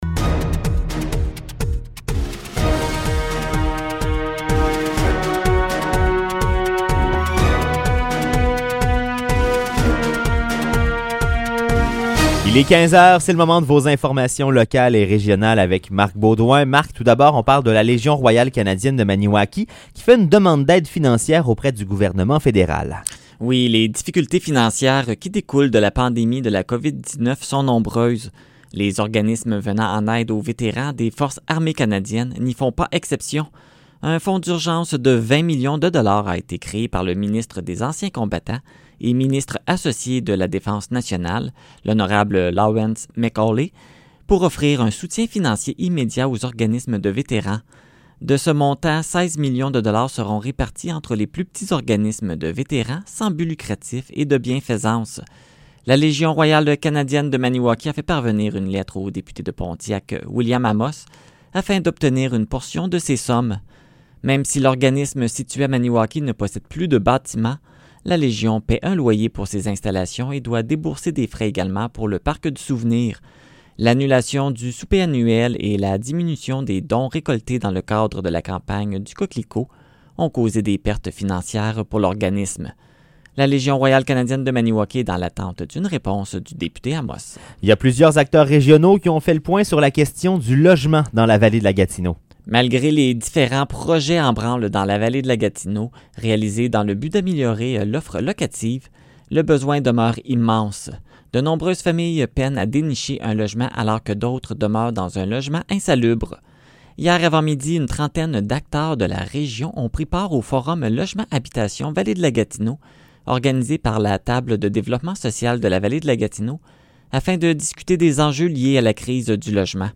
Nouvelles locales - 20 novembre 2020 - 15 h